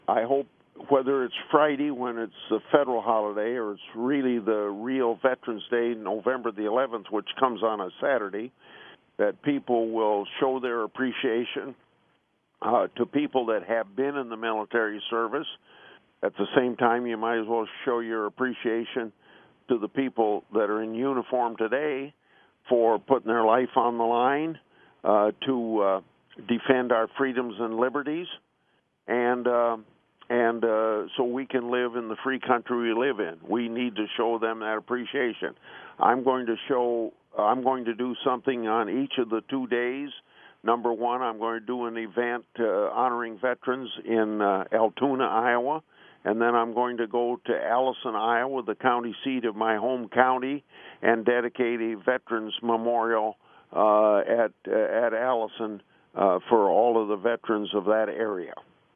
Statement on Veterans Day 2017